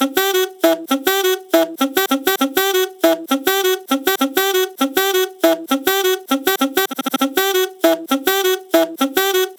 Em_-_100_-_Sax_Loop_04.wav